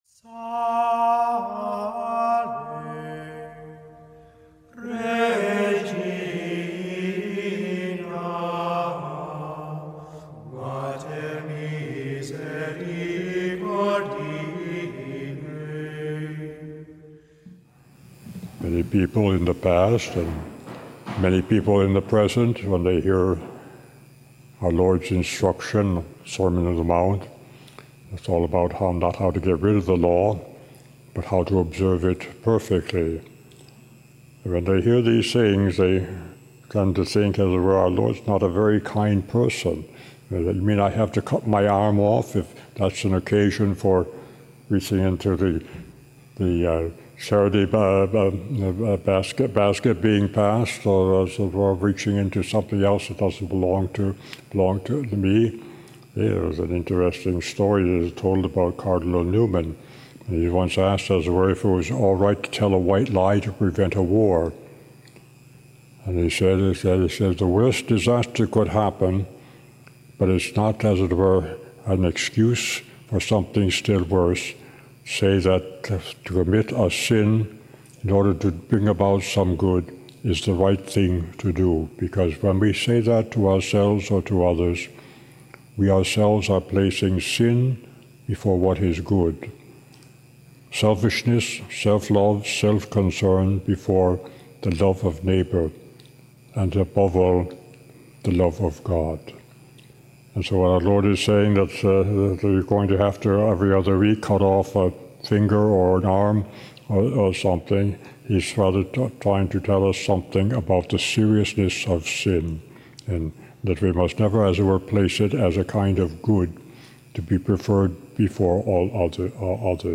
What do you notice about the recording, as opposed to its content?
Mass: Friday 10th Week of Ordinary Time - Wkdy Readings: 1st: 1ki 19:9, 11-16 Resp: psa 27:7-8, 8-9, 13-14 0 Gsp: mat 5:27-32 Audio (MP3) +++